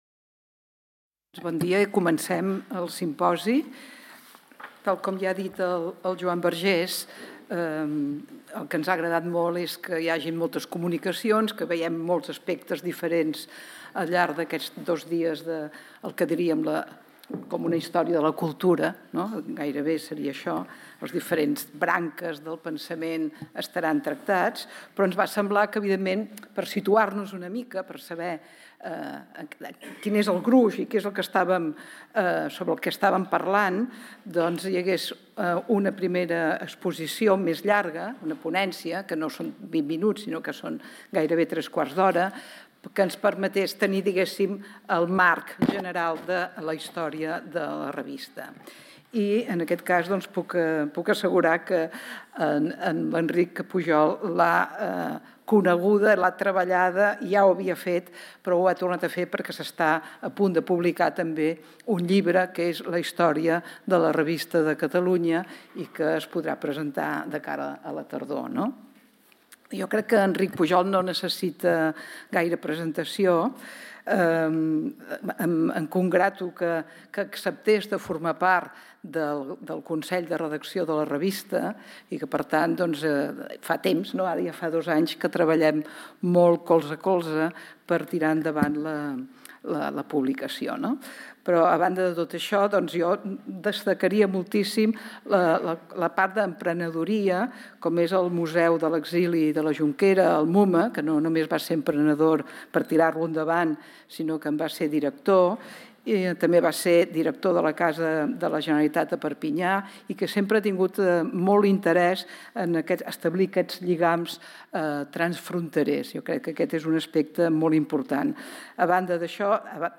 Conferència
en el marc del Simposi Trias 2024 sobre el centenari de la Revista de Catalunya